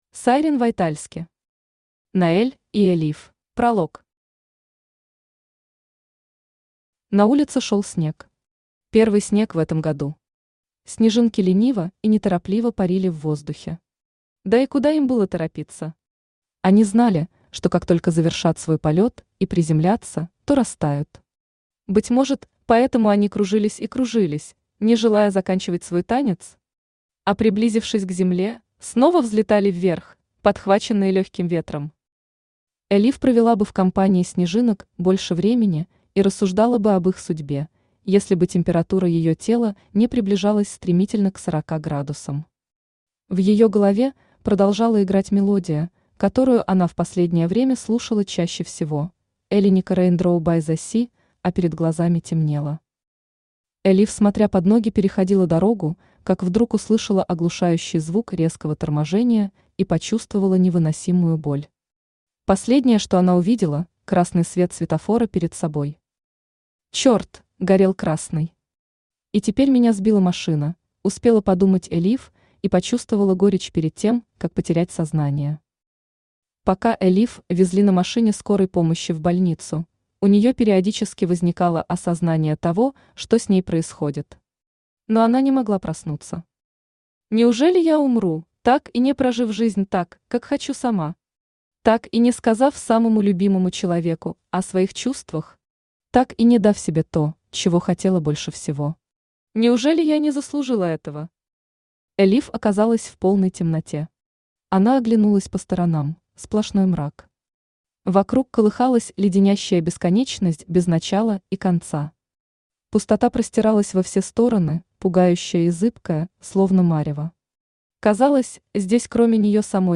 Аудиокнига Ноэль и Элиф | Библиотека аудиокниг
Aудиокнига Ноэль и Элиф Автор Сайрин Вайтальски Читает аудиокнигу Авточтец ЛитРес.